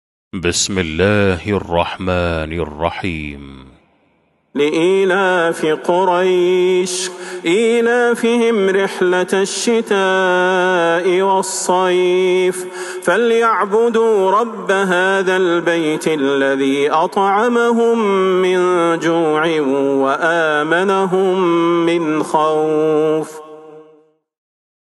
سورة قريش Surat Quraysh > مصحف تراويح الحرم النبوي عام 1443هـ > المصحف - تلاوات الحرمين